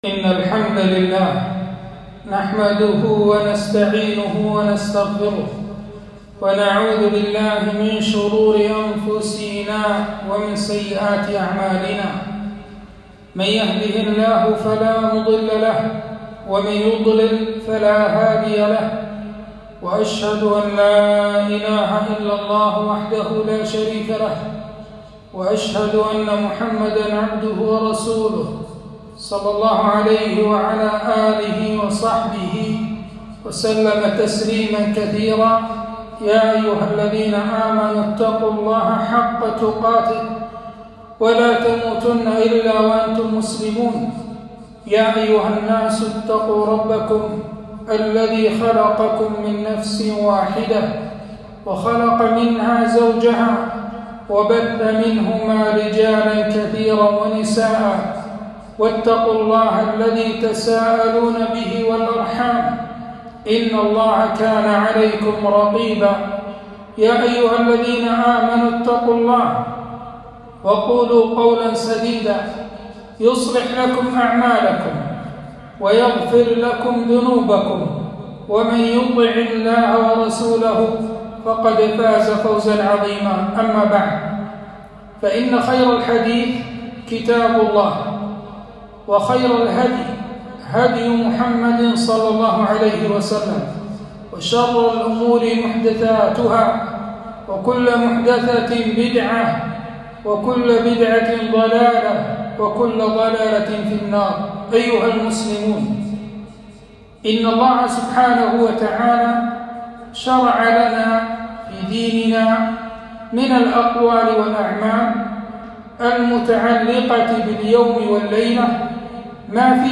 خطبة - أذكار اليوم والليلة